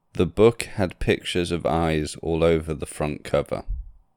Dictation 1